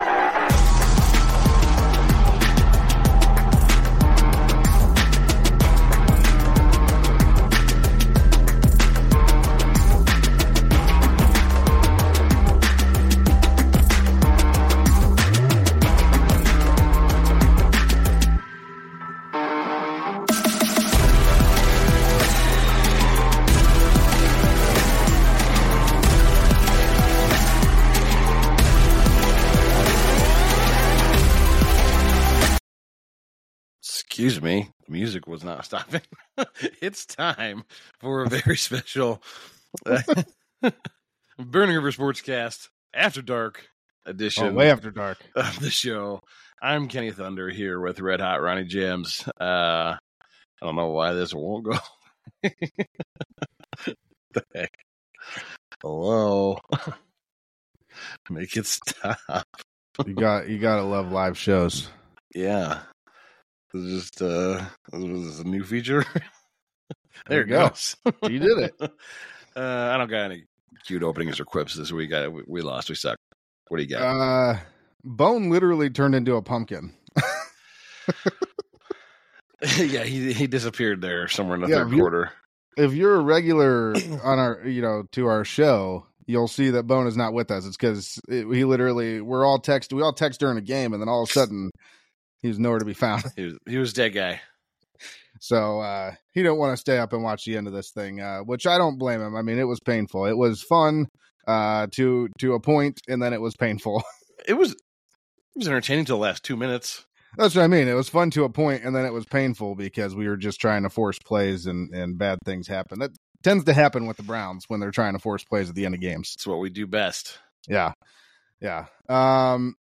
It's time! Time for Burning River Sportscast to go live again! Join as we discuss the Week 13 Cleveland Browns loss to the Denver Broncos.